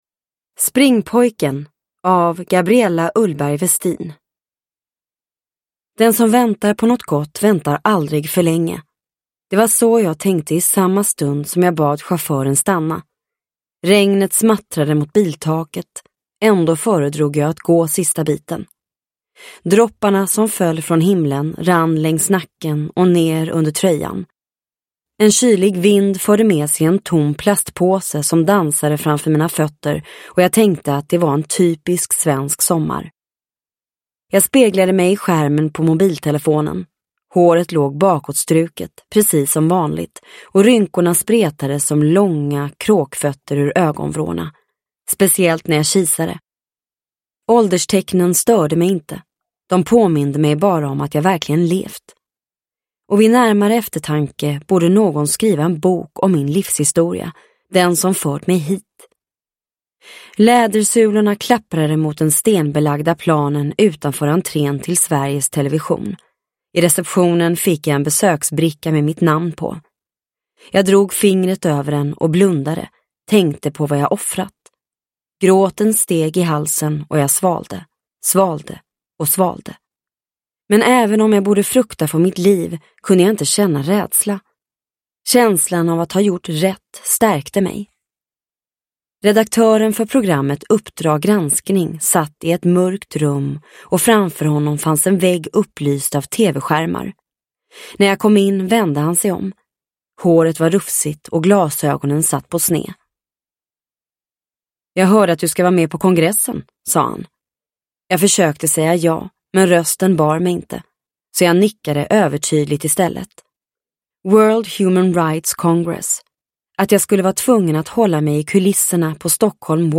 Springpojken – Ljudbok – Laddas ner